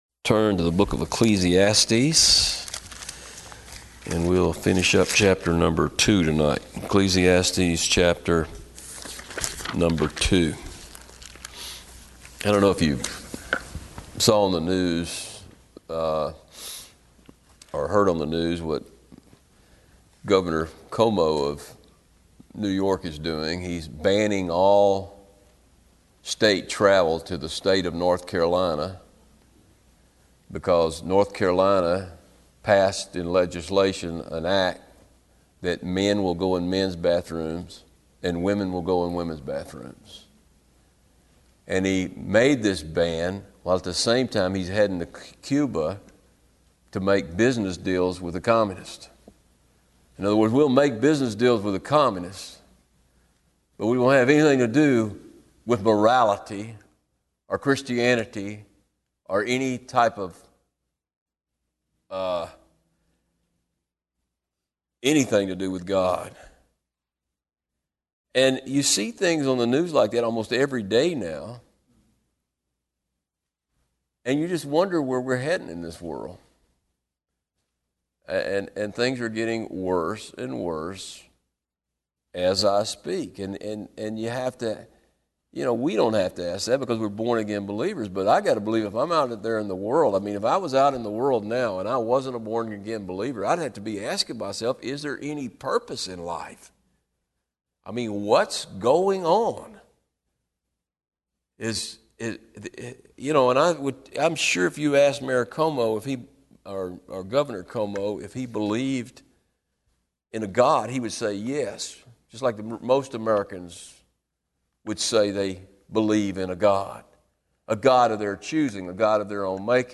These teachings on Ecclesiastes are from Wednesday evening service.